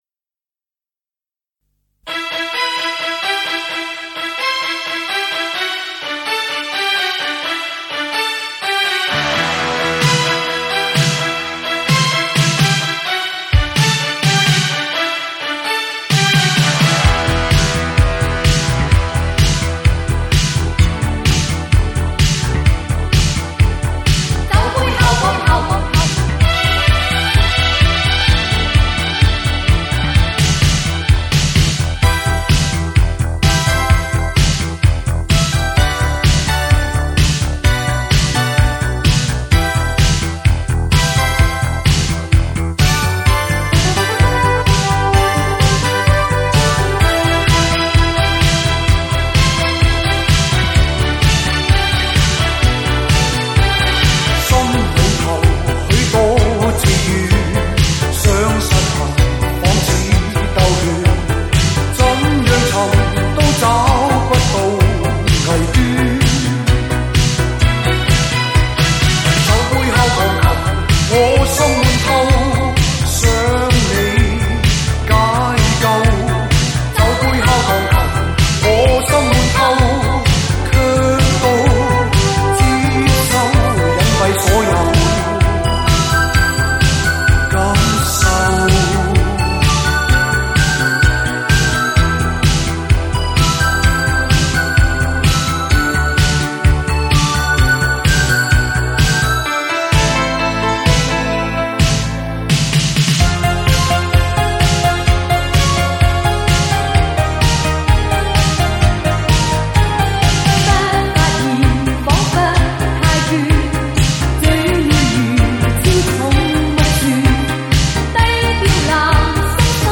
经典劲爆动感粤语歌精选集